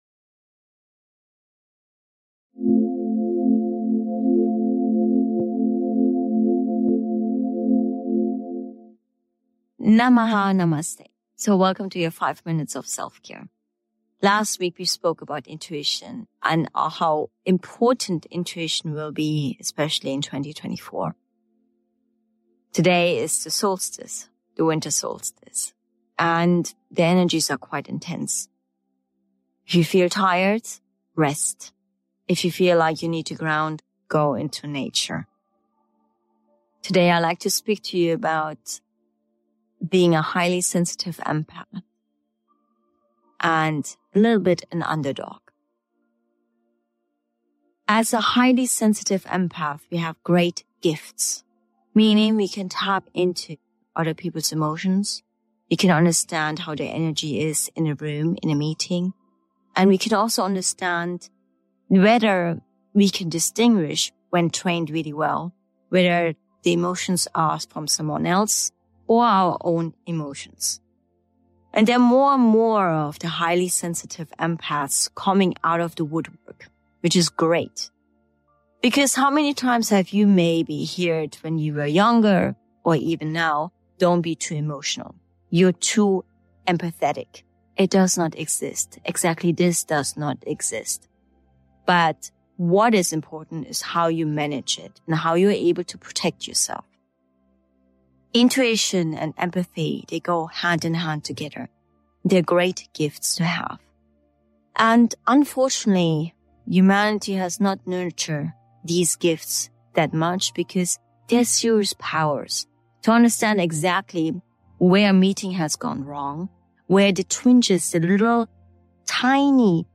soulful conversation